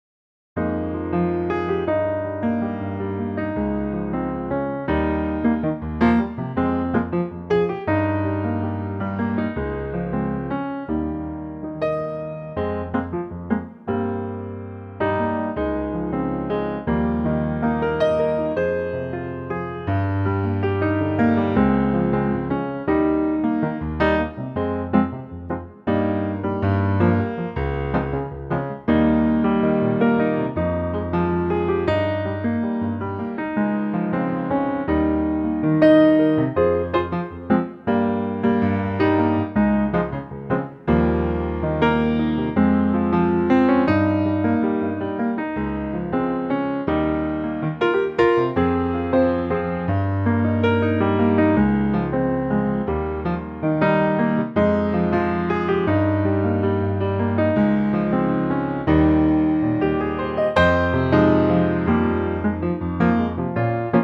Unique Backing Tracks
key - Fm - vocal range - Ab to Ab